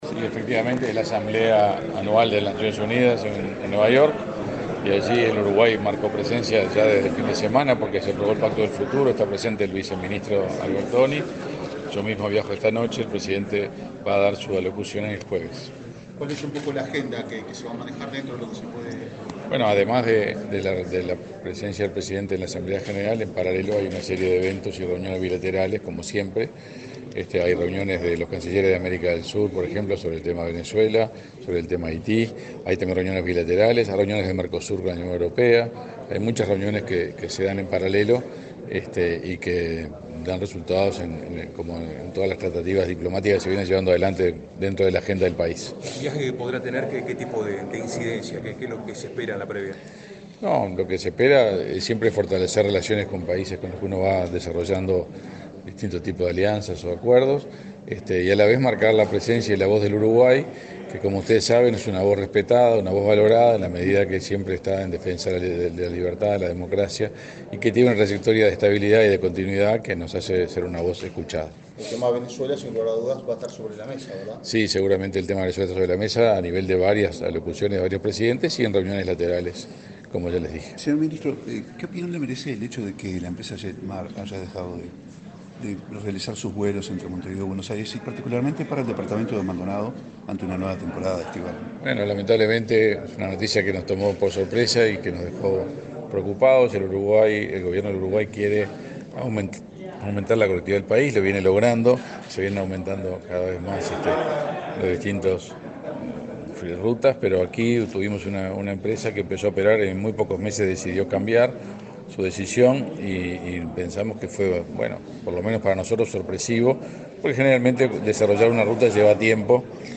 Declaraciones del canciller Omar Paganini
El canciller Omar Paganini dialogó con la prensa, luego de participar en la apertura de la Décima Conferencia Bienal de Aguas Internacionales del